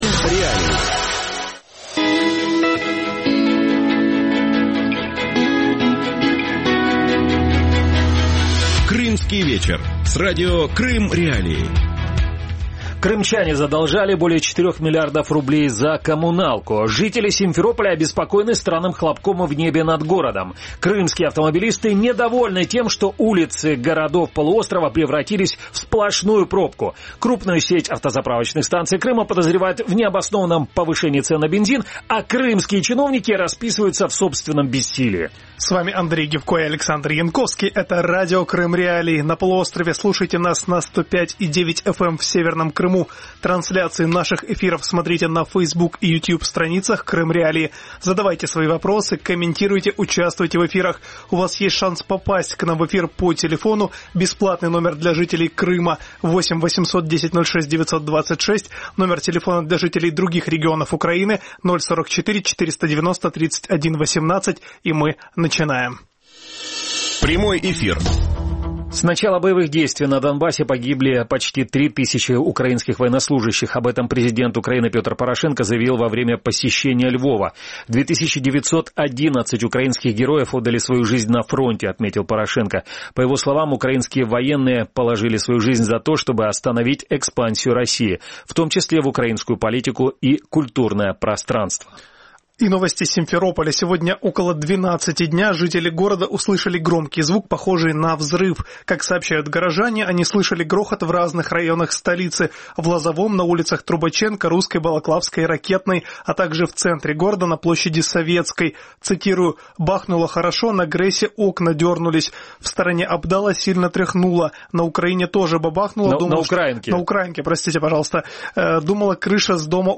«Крымский вечер» – шоу, которое выходит в эфир на Радио Крым.Реалии в будни с 18:30 до 19:30.